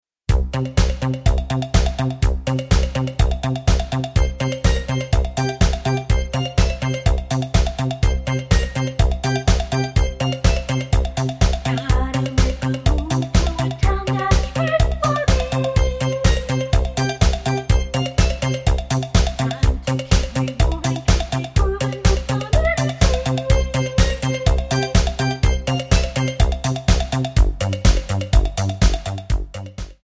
Backing track Karaoke
Pop, Disco, 1970s